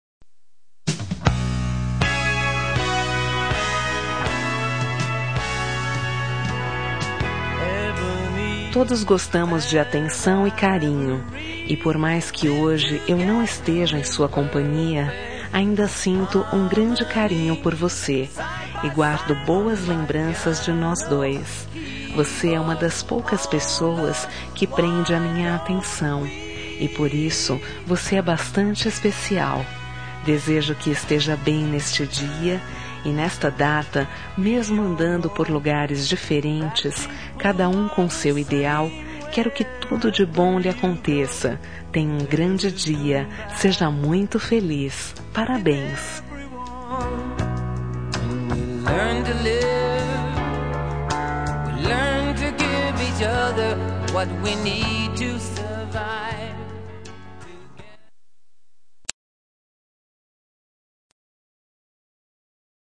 Telemensagem Aniversário de Ex. – Voz Feminina – Cód: 1352